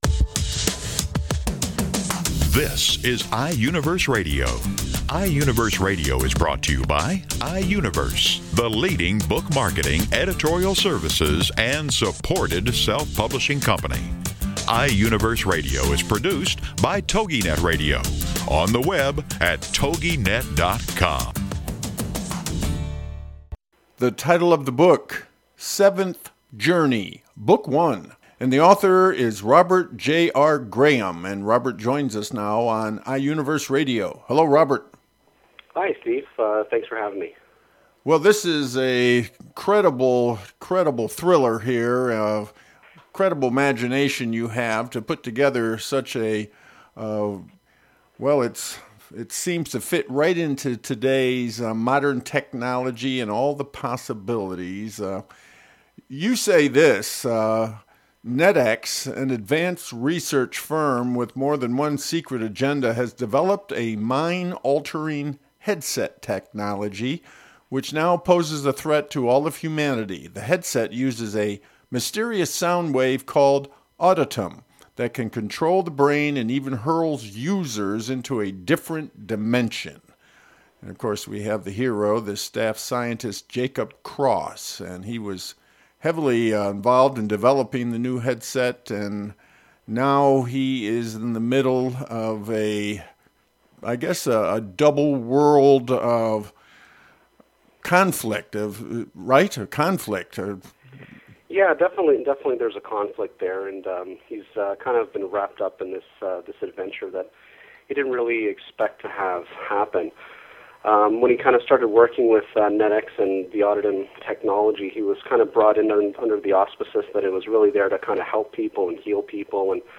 I was certainly nervous, as the minutes leading up to the phone call seemed to take years. All of this for a 15 minute interview, and once it started, it was over before I knew it.